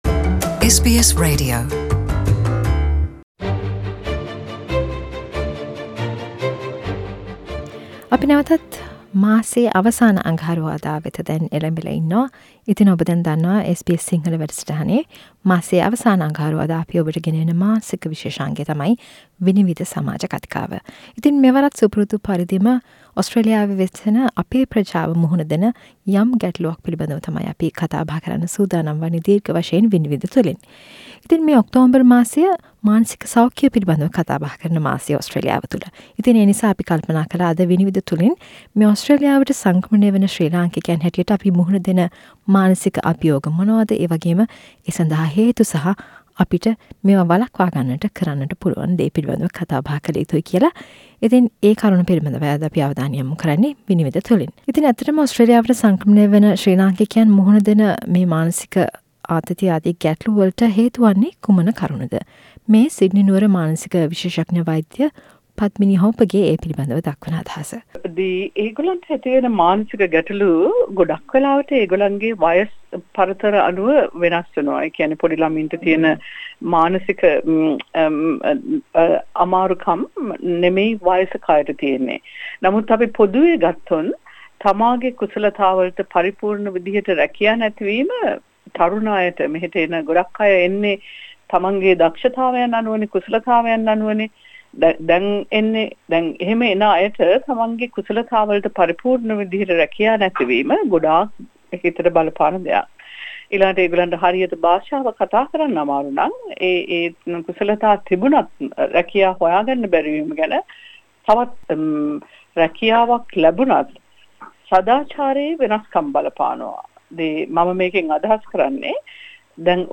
SBS සිංහල සේවය මඟින් ඕස්ට්‍රේලියාවේ වසන අපේ ශ්‍රී ලාංකික ජන සමාජයට බලපාන කරුණු පිළිබඳව සාකච්චා කරන මාසික සමාජ කථිකාව "විනිවිද", සැම මසකම අවසාන අගහරුවාදා වැඩසටහනින් ඔබ වෙත ගෙන එයි.